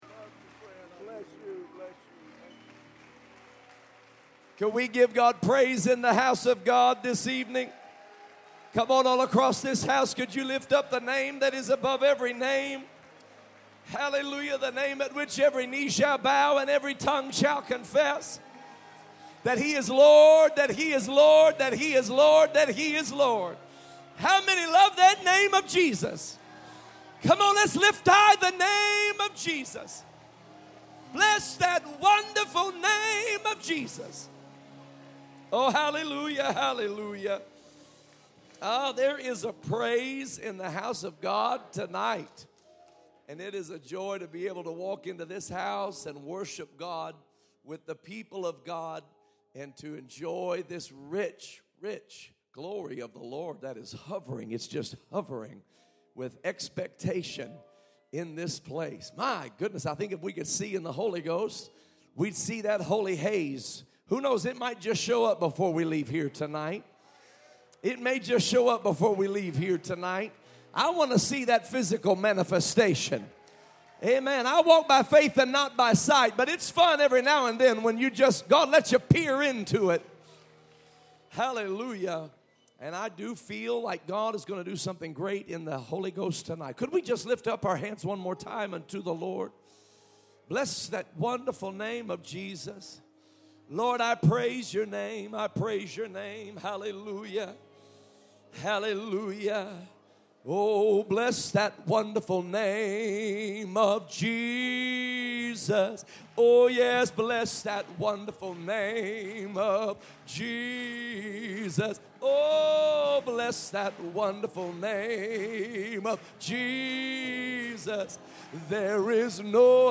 Sermon Archive | Illinois District
Camp Meeting 24 (Friday PM)